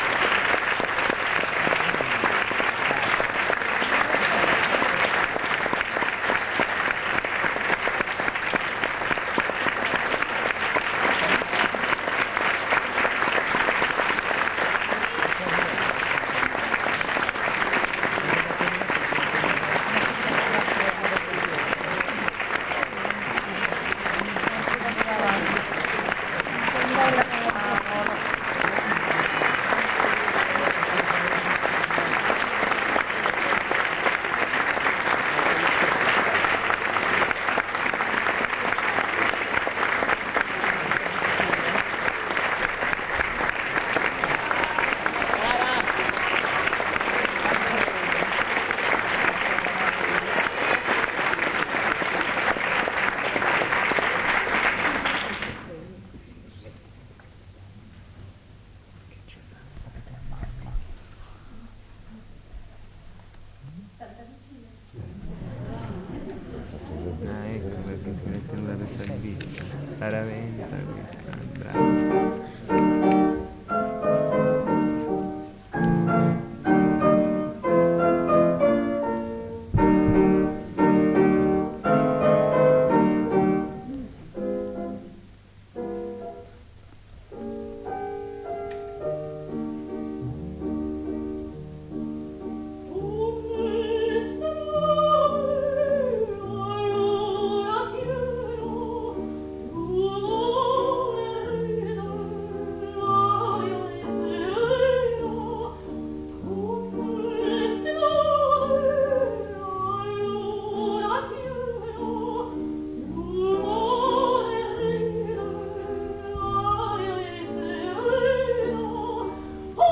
Martine Dupuy, mezzosoprano
pianoforte
Roma, Teatro dei Satiri